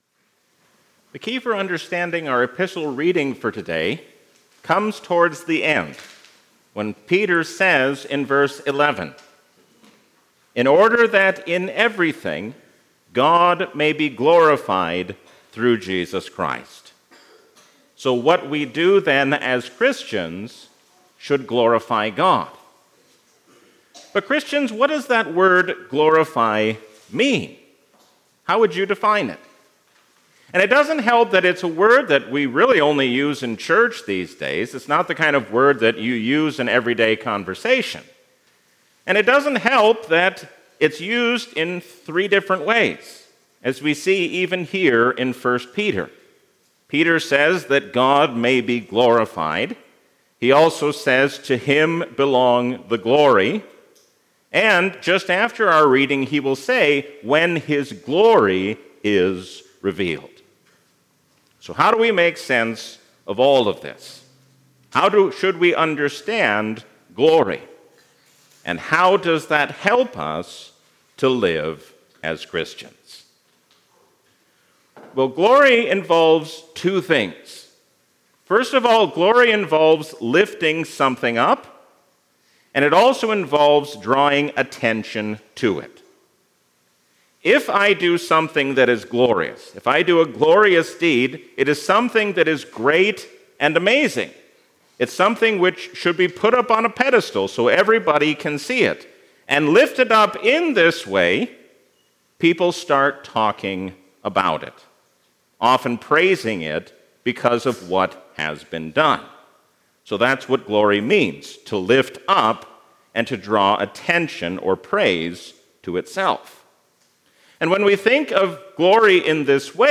A sermon from the season "Trinity 2025." No matter how much the world changes, we can be confident because Jesus does not change.